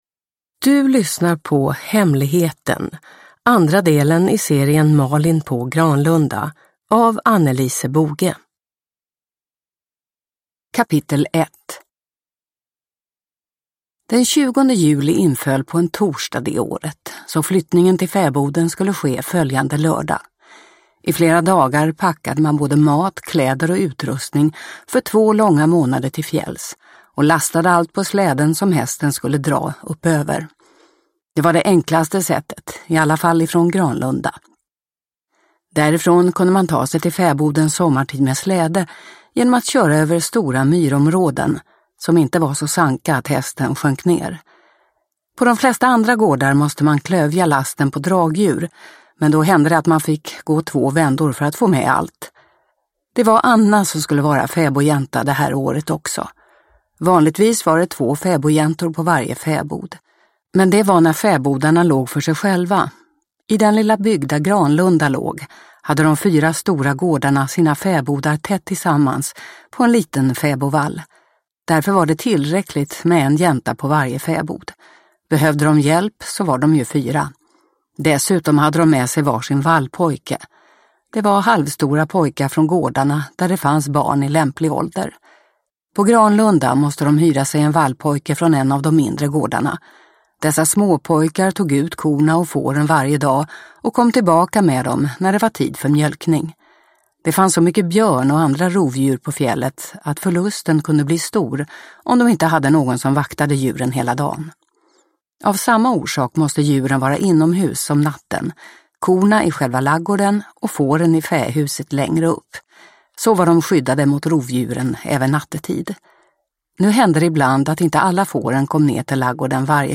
Hemligheten – Ljudbok – Laddas ner